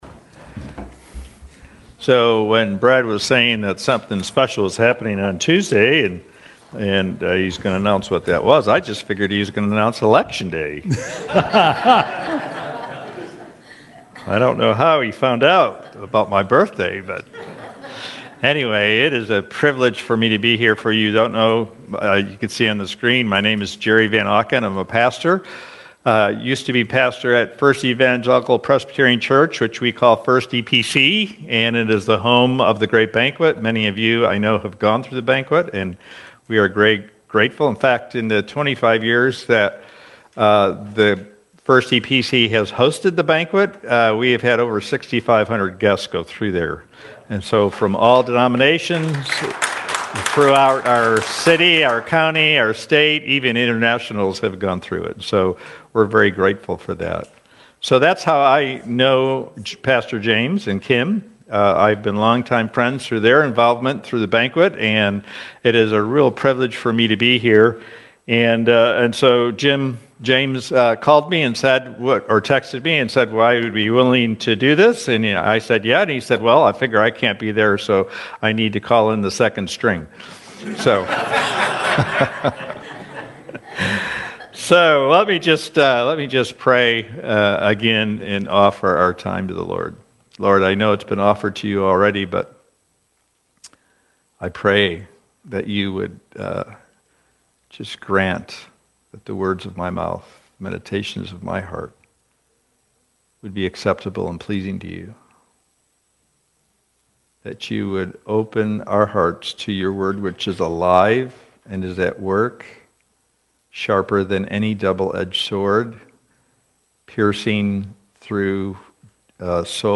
Sermons Archive - Here's Hope Church